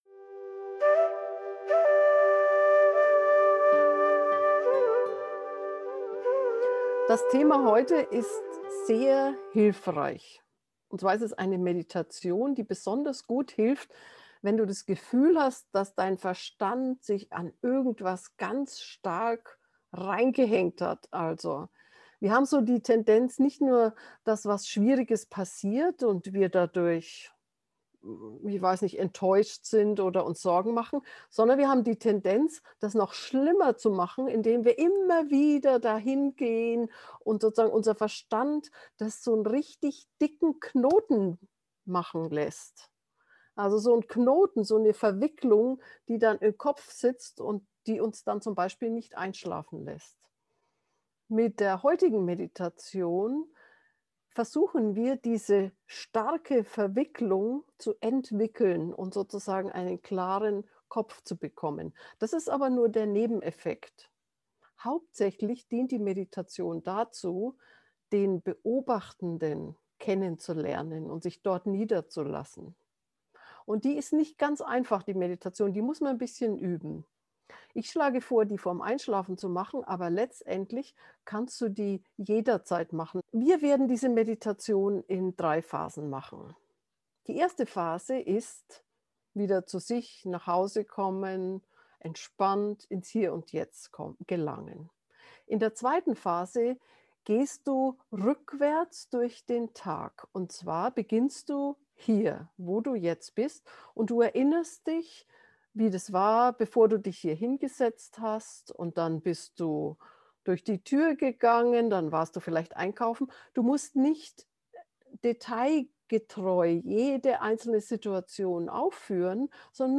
einschlafen-verstrickungen-loesen-gefuehrte-meditation